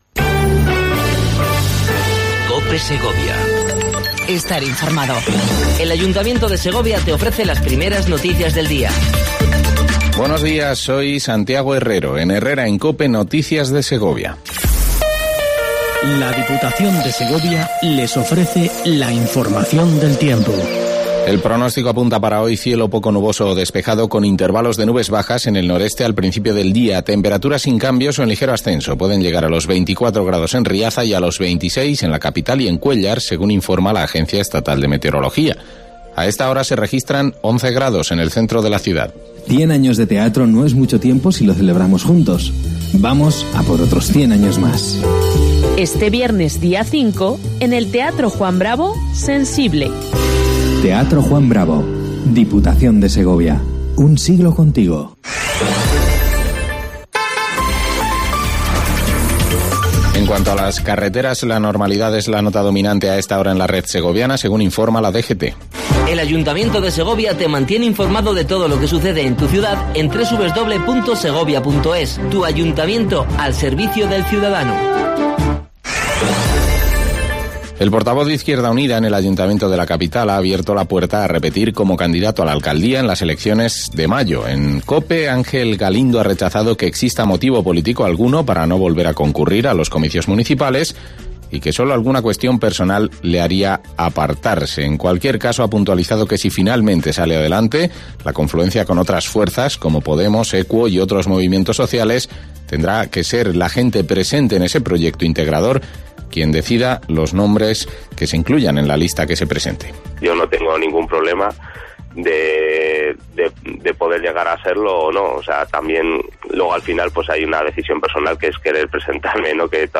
INFORMATIVO 07:55 COPE SEGOVIA 04 10 18
AUDIO: Primer informativo local cope segovia